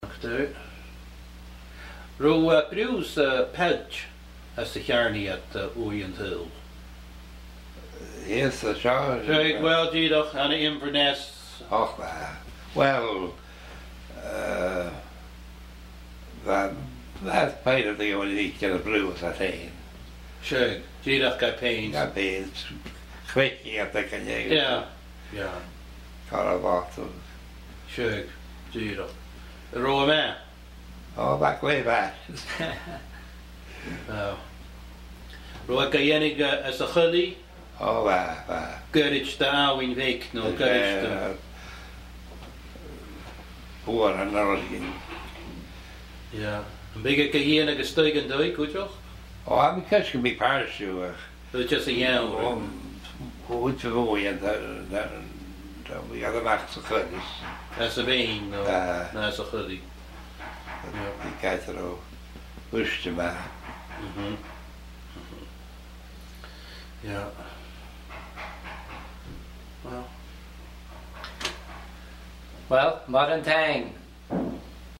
Glenora Distillery, An Gleann Dubh
Agallamh